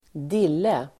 Uttal: [²d'il:e]